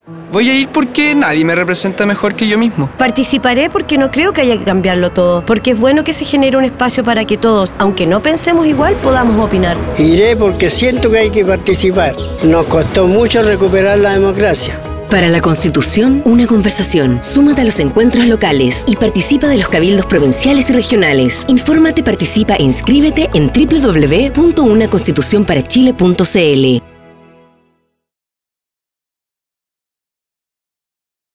Audio con distintas voces testimoniales que llaman a la participación en el proceso constituyente, especialmente en los encuentros locales, cabildos provinciales y regionales 9.